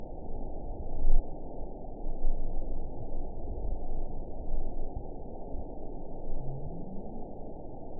event 912637 date 03/30/22 time 23:28:51 GMT (3 years, 1 month ago) score 8.49 location TSS-AB01 detected by nrw target species NRW annotations +NRW Spectrogram: Frequency (kHz) vs. Time (s) audio not available .wav